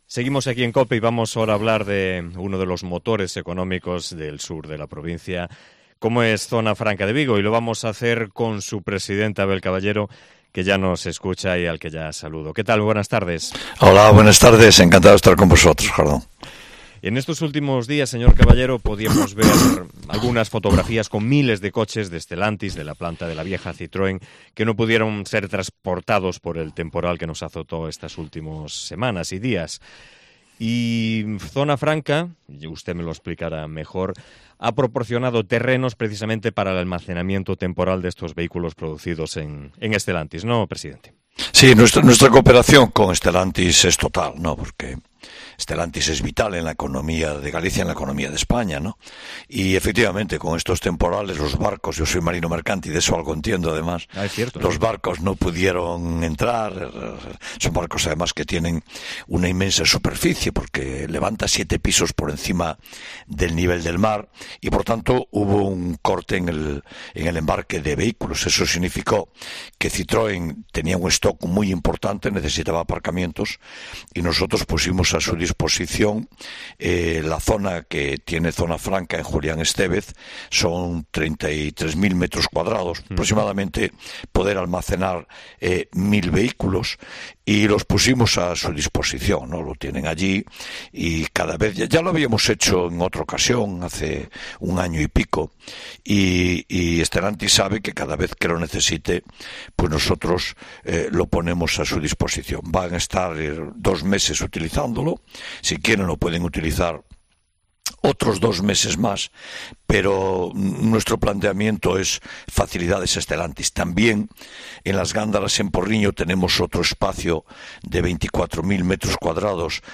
Entrevista con Abel Caballero, presidente de Zona Franca de Vigo